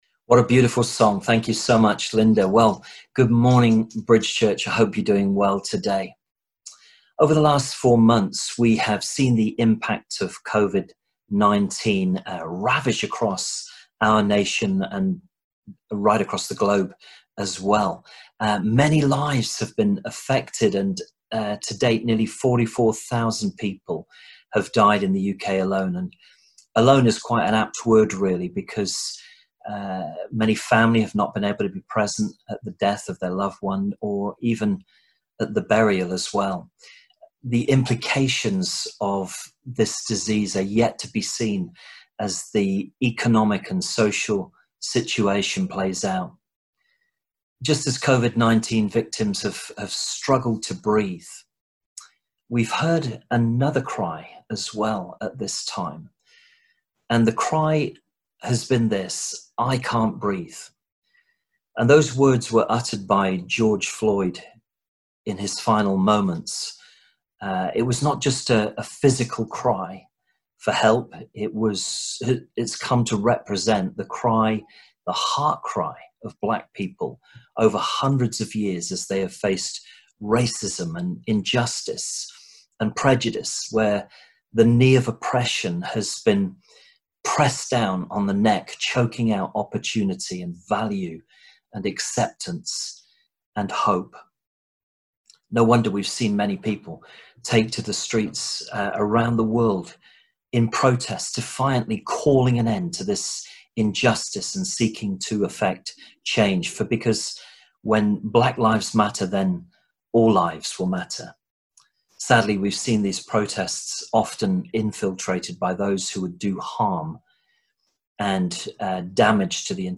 A message from the series "Sunday Morning." In this message we look at how the gospel of grace addresses the issue of race, and we consider the way we should respond as Christians to the discrimination we encounter in our world.